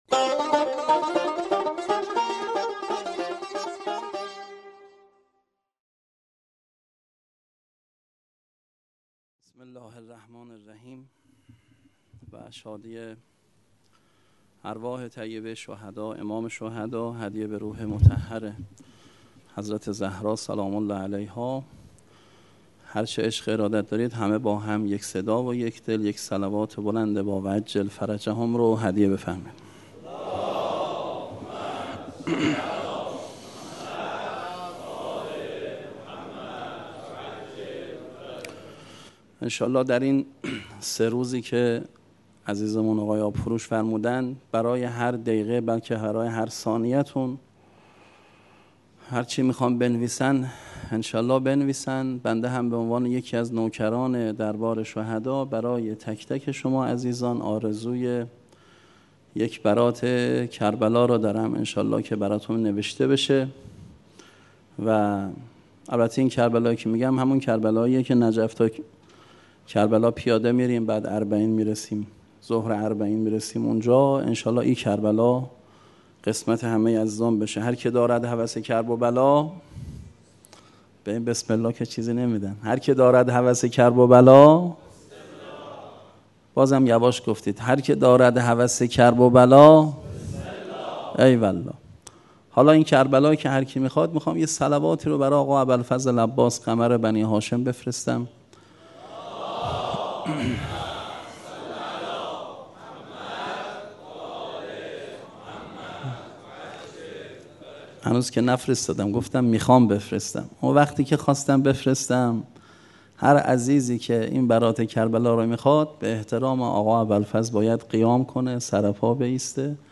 سخنرانی | تحلیل وضعیت منطقه و فتنه های آینده